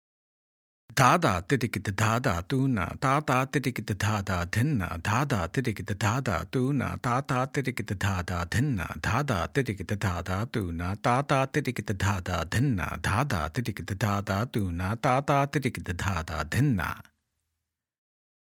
2x Speed – Spoken